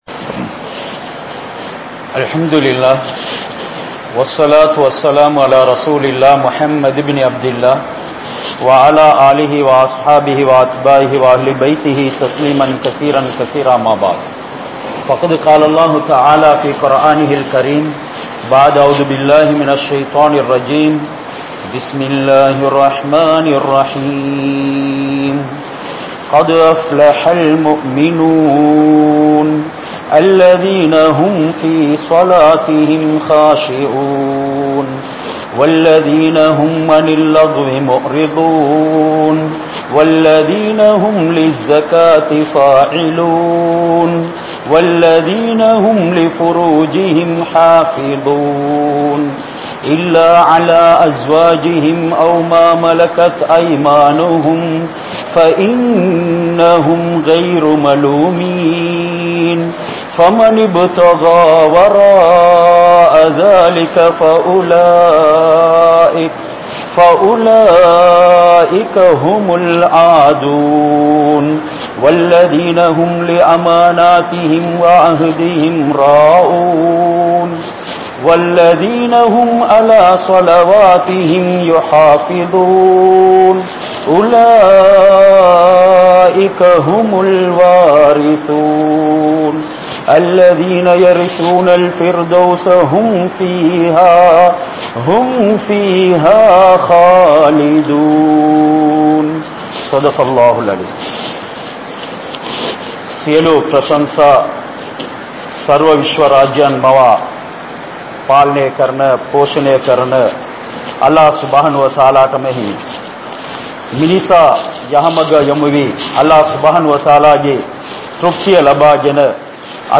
Muminin Panpuhal (முஃமினின் பண்புகள்) | Audio Bayans | All Ceylon Muslim Youth Community | Addalaichenai
Colombo, Baththaramulla, Jayawadanagama Jumua Masjidh